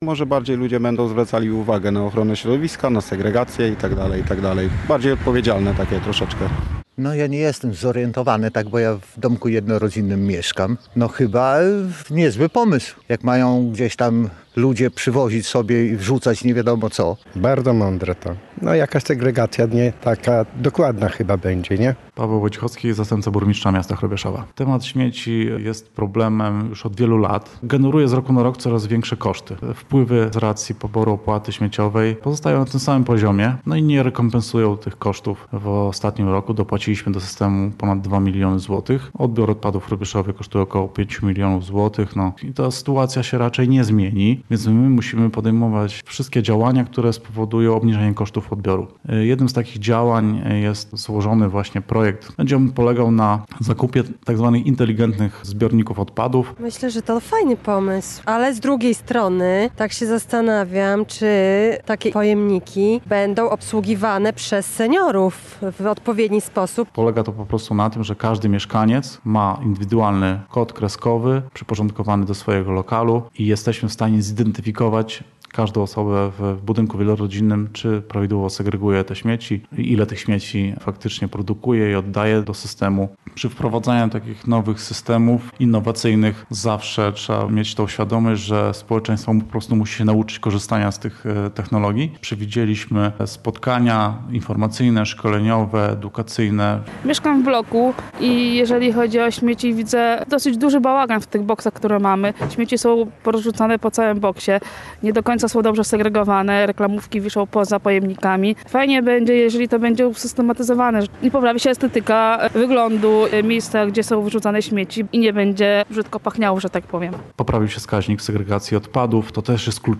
Z planowanych rozwiązań są zadowoleni mieszkańcy miasta: – Może ludzie będą bardziej zwracali uwagę na ochronę środowiska, na segregację.
– Temat śmieci jest problemem już od wielu lat, z roku na rok generuje coraz większe koszty – mówi Paweł Wojciechowski, zastępca burmistrza Hrubieszowa.